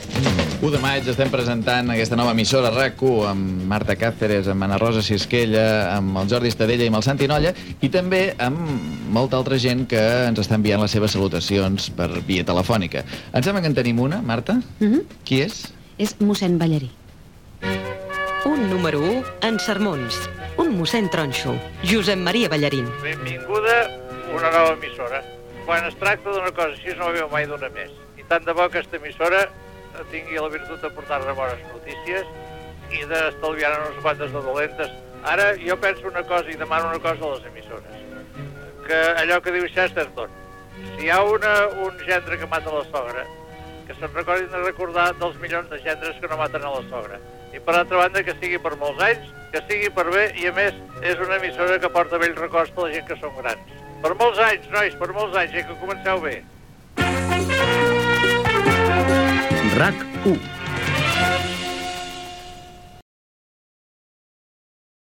FM
Emissió de presentació de RAC 1 el dia abans de la seva inauguració oficial.